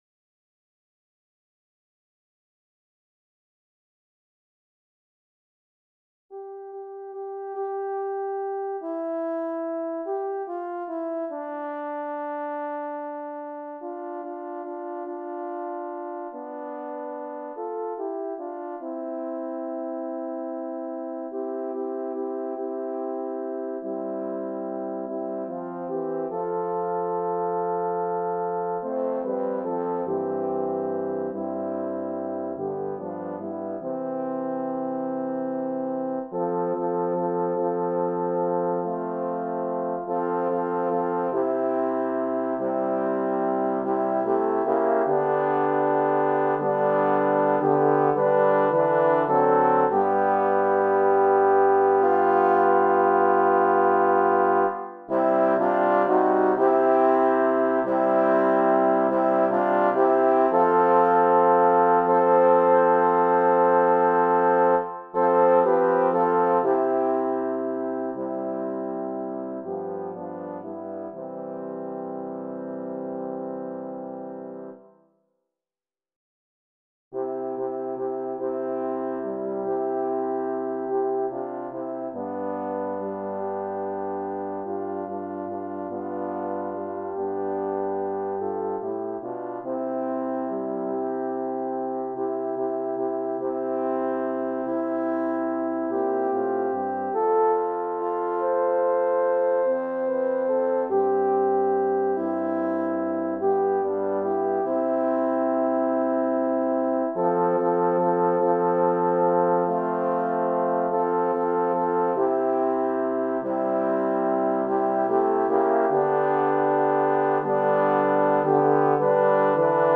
Through the years, church choir directors changed many aspects of the hymns, especially fermatas and dynamics. It is a real challenge to write these horn quartets like we sing them and not like they are in the hymn book.